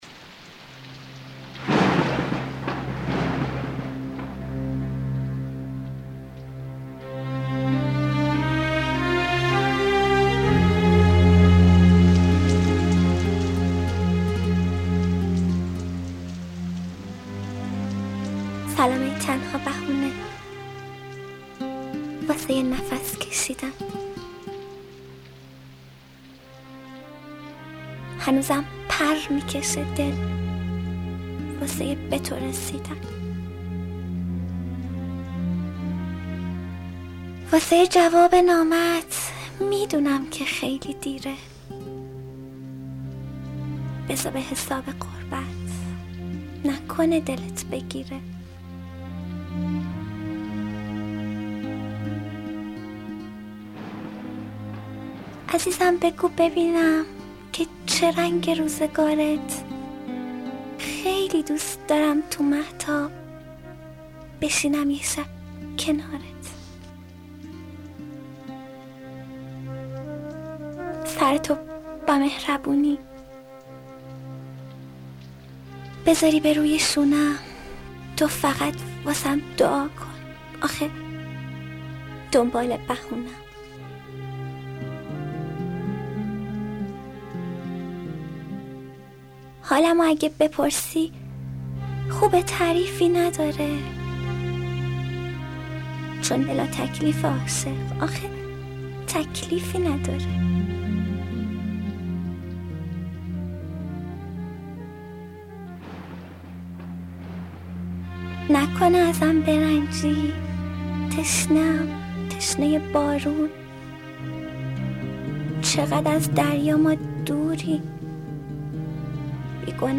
دانلود دکلمه جواب نامت با صدای مریم حیدر زاده
گوینده :   [مریم حیدرزاده]